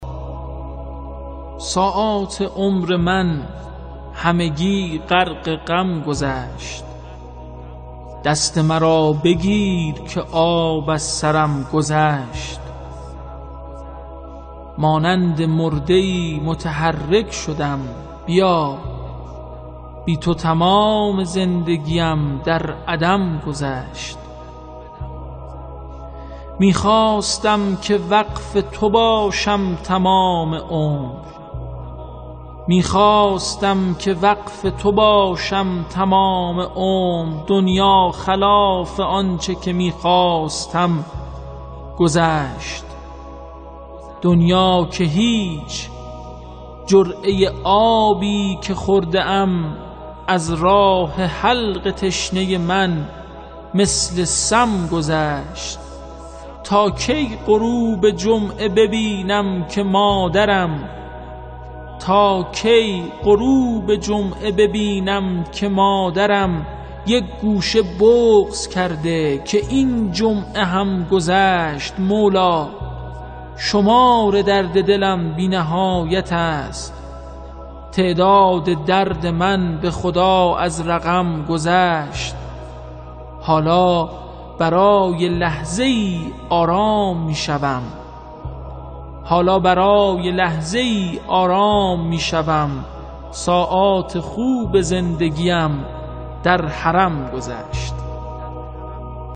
خواندن شعر توسط سید حمیدرضا برقعی(شاعر)